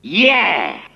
One of Wario's voice clips in Mario Party 4